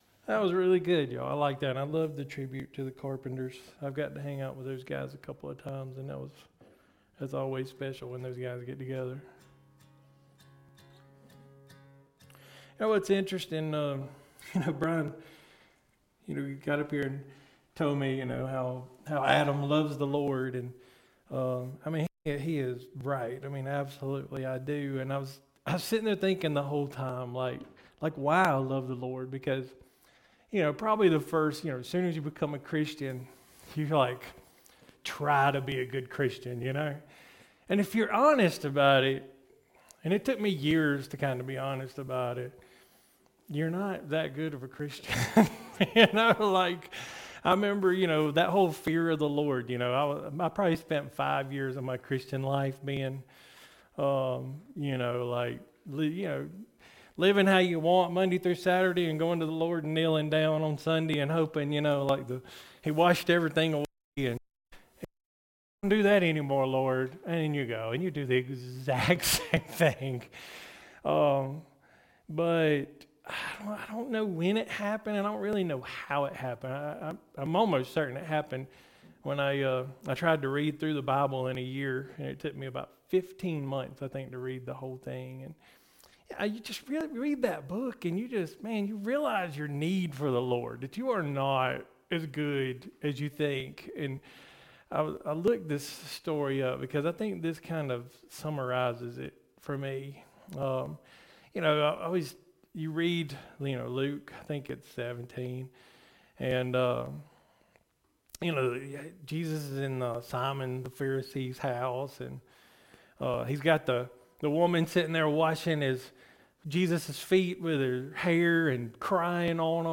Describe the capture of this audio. Occasion - Sunday Worship